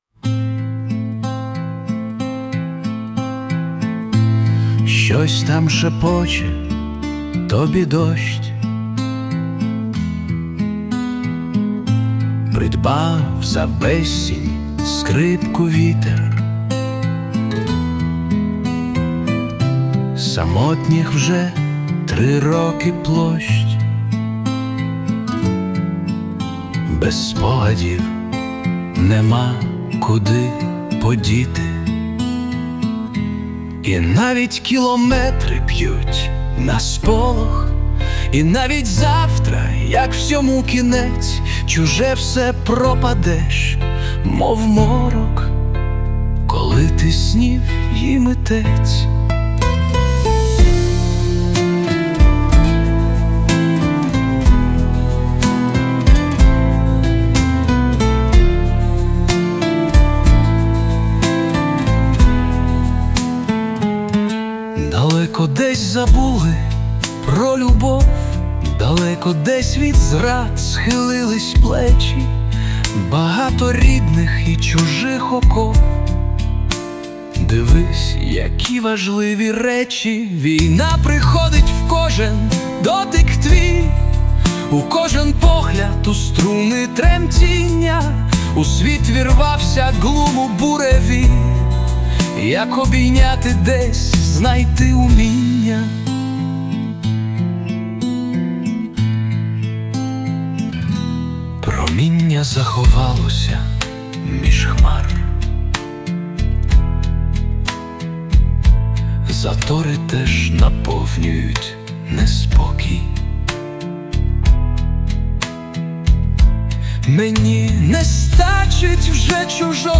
Кавер
Гарна пісня. 16